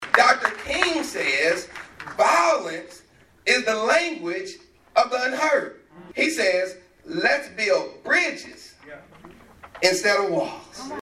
Monday morning, the community gathered at HCC to honor Dr. Martin Luther King Jr.’s vision for justice and equality.